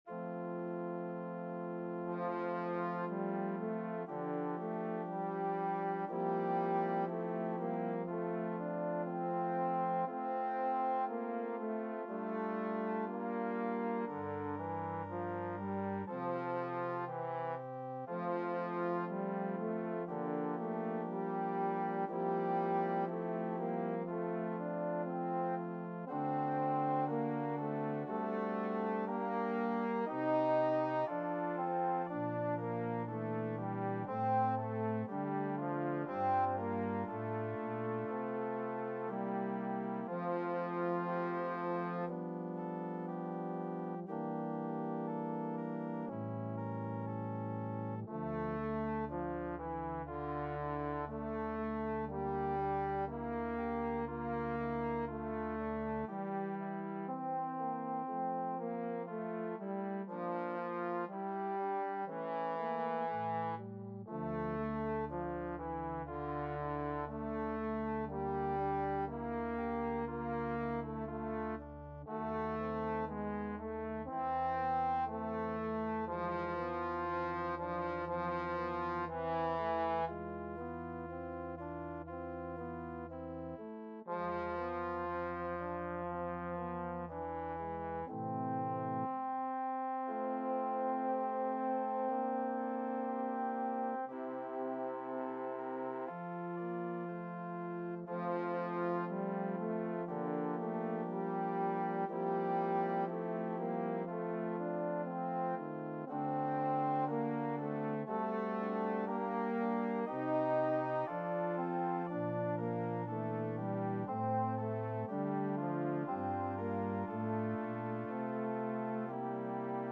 2/4 (View more 2/4 Music)
C4-Eb5
Classical (View more Classical Trombone Music)